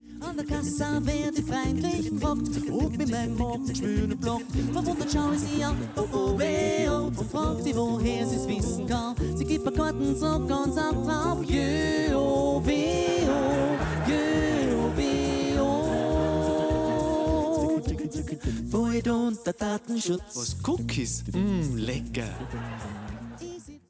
a cappella-Konzertpackage